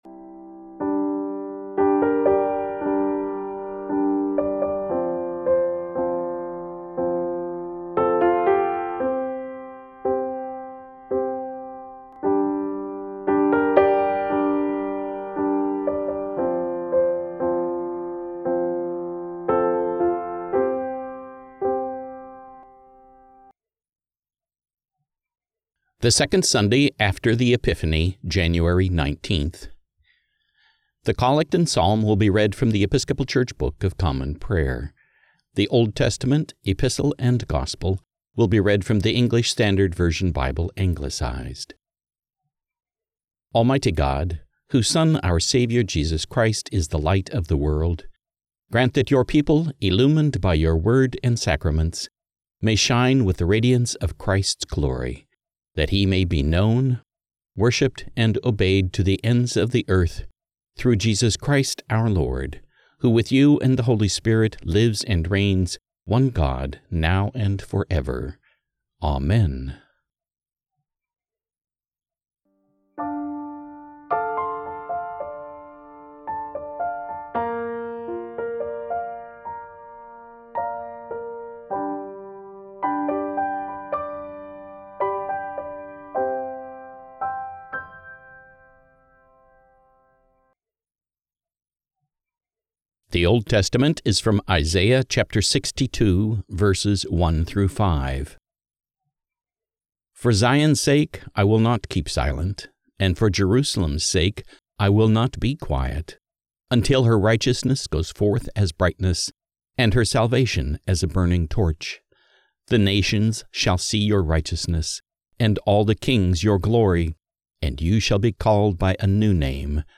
The Collect and Psalm will be read from The Episcopal Church Book of Common Prayer The Old Testament Epistle and Gospel will be read from the English Standard Version Bible Anglicized